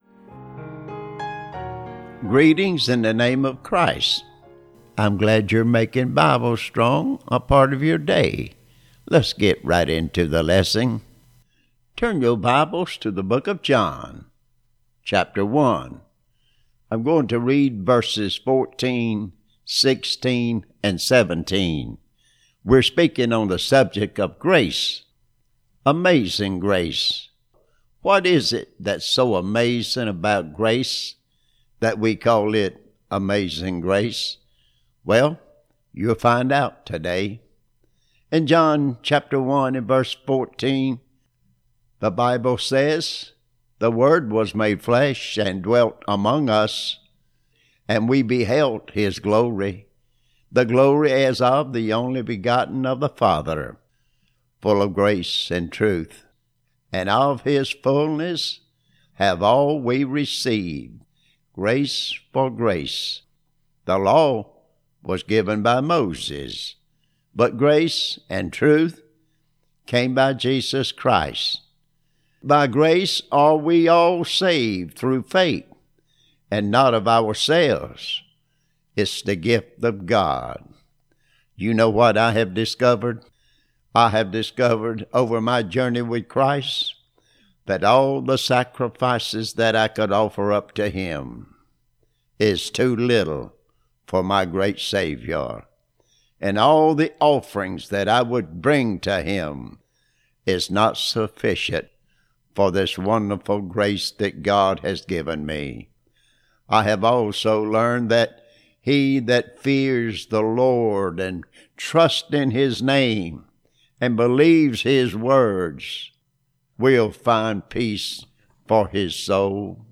Lesson 5